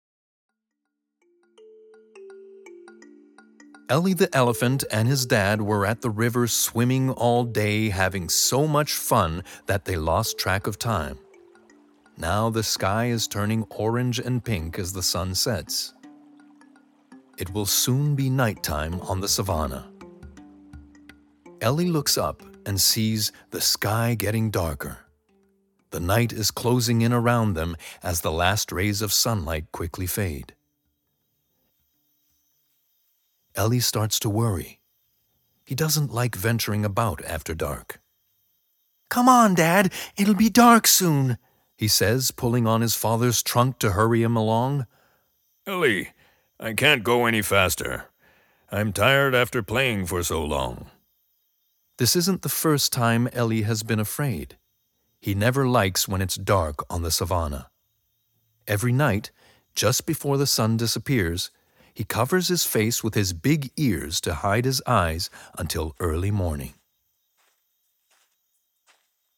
Narration 2 - EN